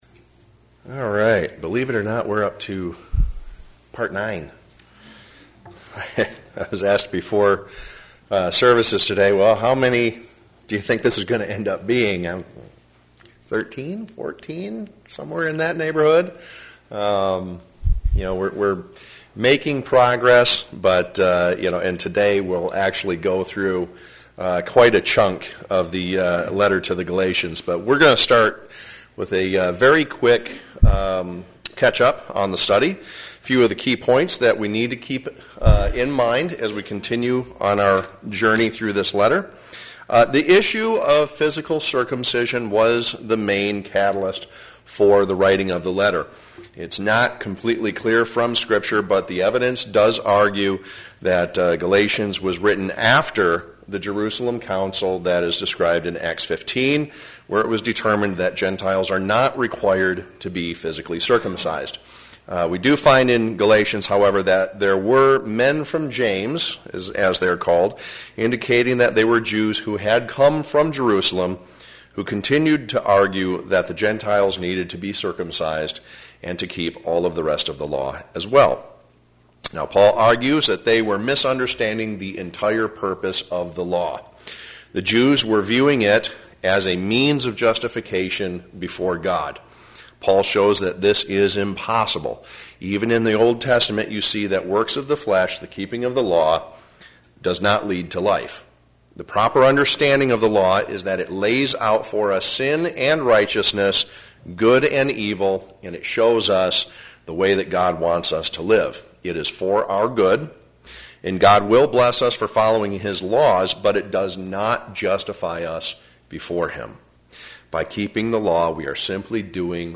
Galatians Bible Study: Part 9